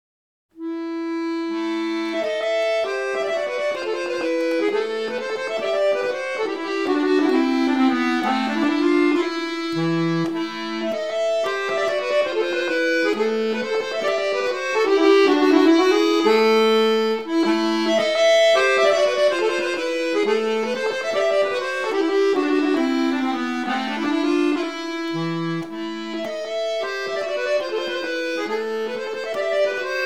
Norwegian folk music